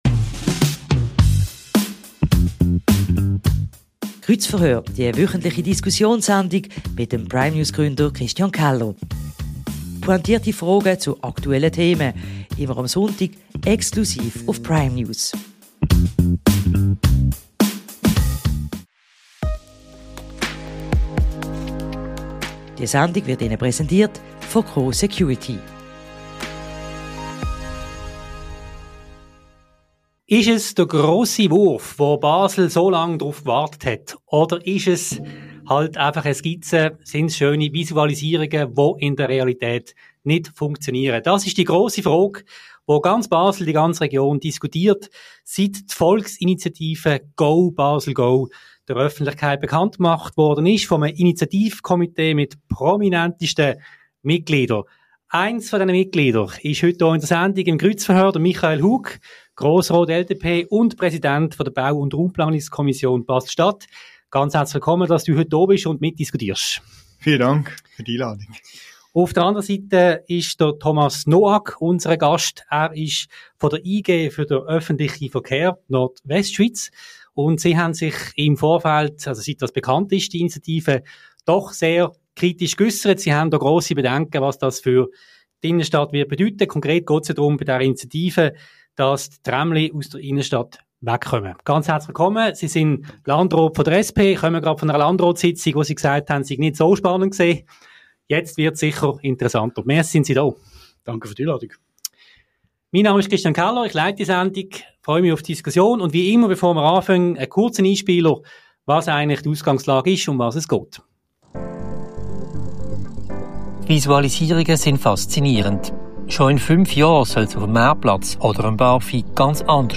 Marktplatz künftig tramfrei? Das Kreuzverhör zur Volksinitiative «Go Basel Go!»